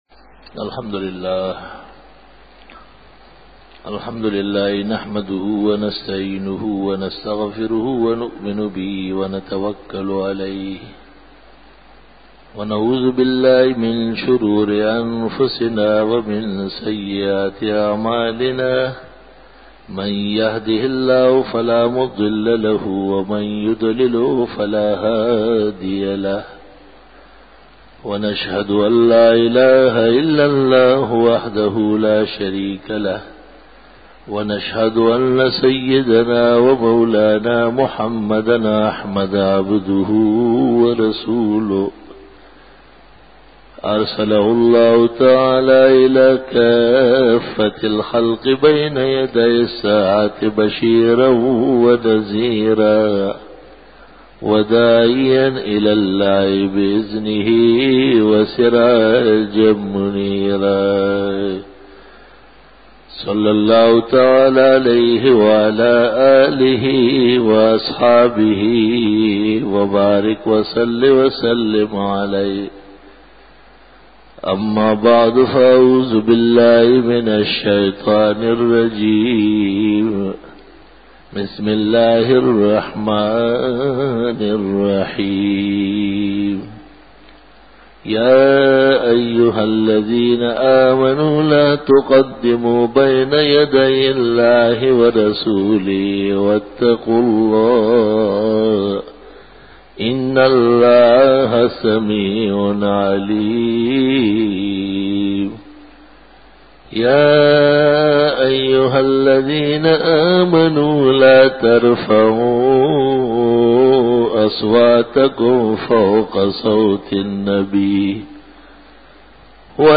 بیان جمعۃ المبارک